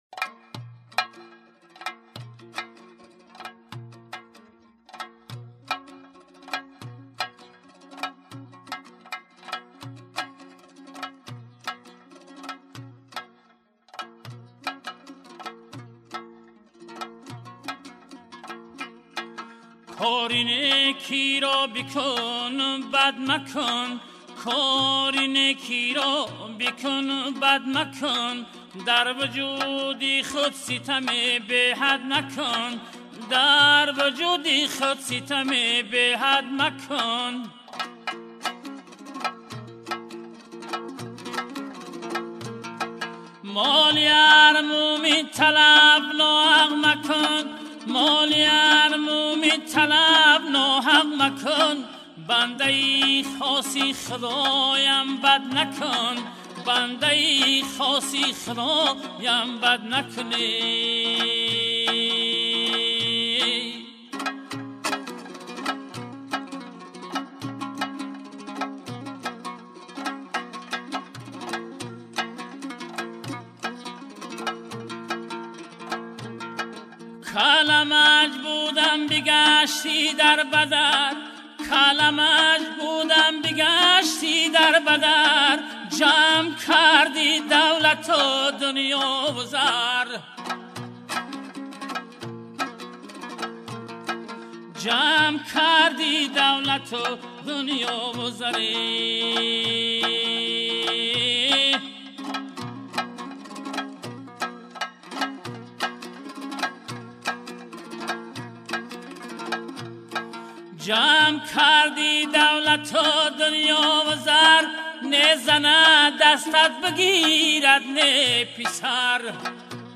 бо садои хонандаи тоҷикистонӣ
Барчасп мусиқӣ